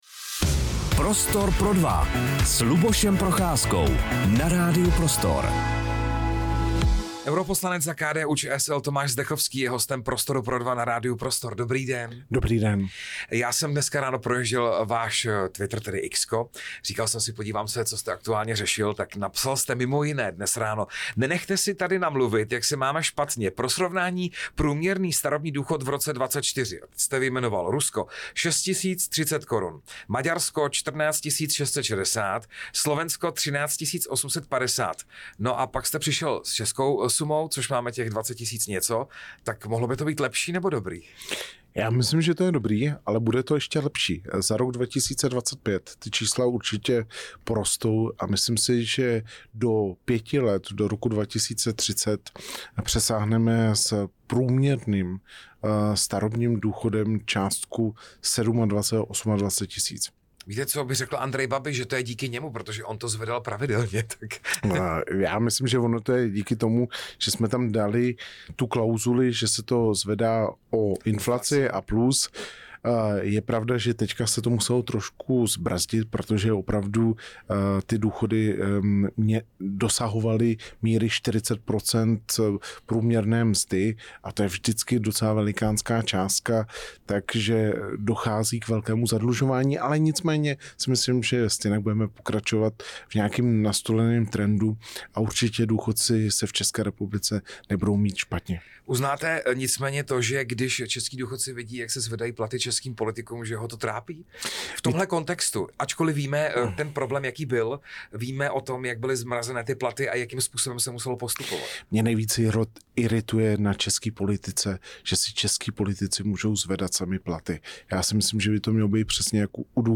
Rozhovor s europoslancem Tomášem Zdechovským | Radio Prostor